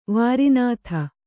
♪ vāri nātha